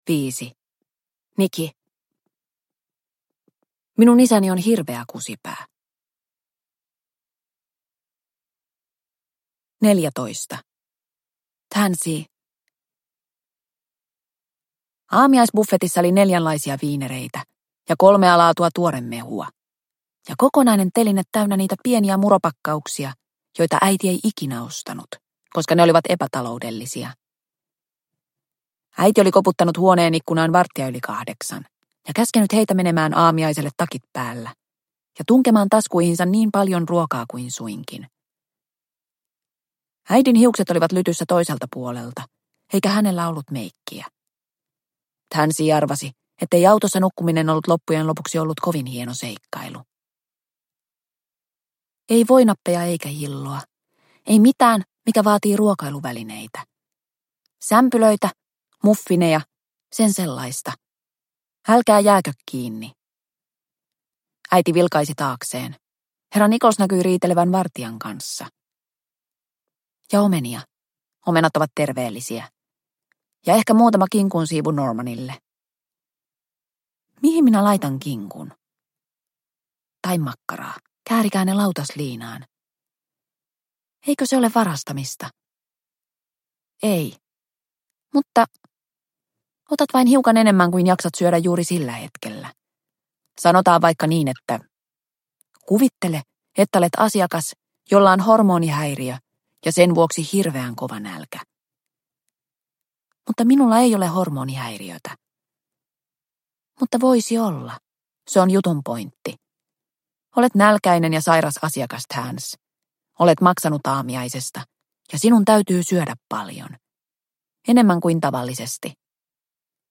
Parillisia ja parittomia – Ljudbok – Laddas ner